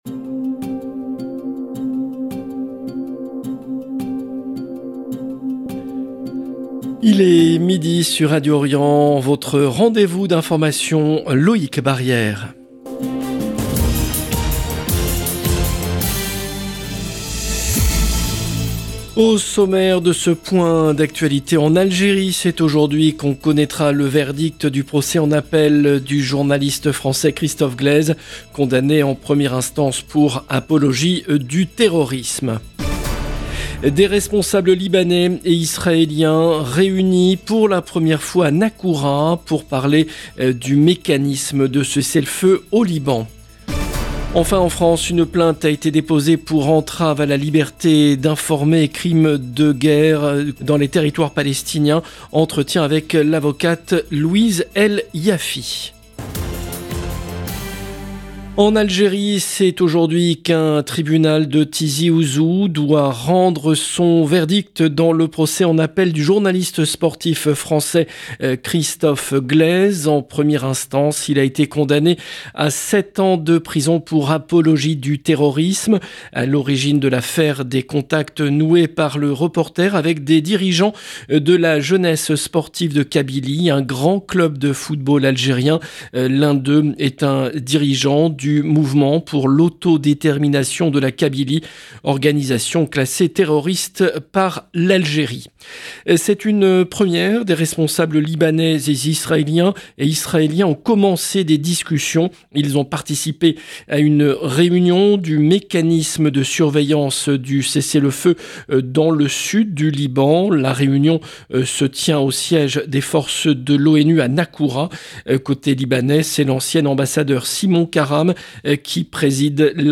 Des responsables libanais et israéliens réunis pour la 1ere fois à Naqoura pour parler du mécanisme de cessez-le-feu au Liban. Enfin, en France, une plainte a été déposée par le SNJ et la FIJ pour entrave à la liberté d’informer et « crimes de guerre » dans les territoires palestiniens. Entretien